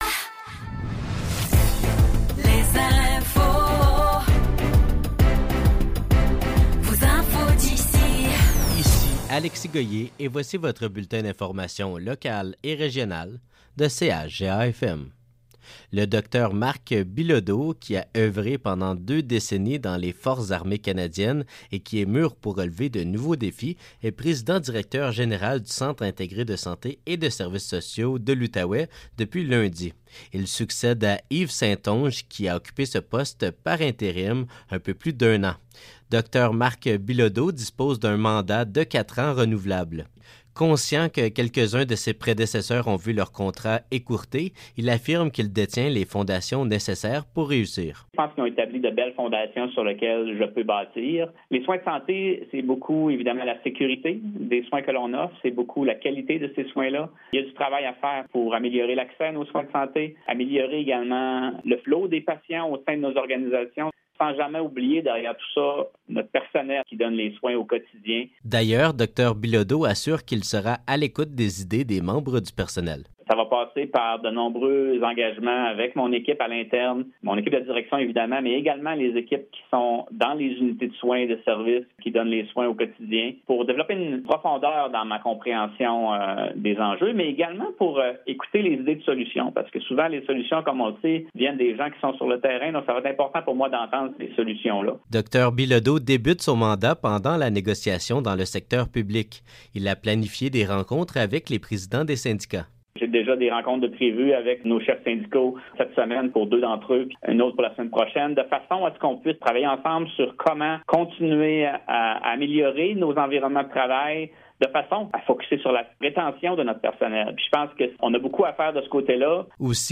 Nouvelles locales - 25 janvier 2024 - 12 h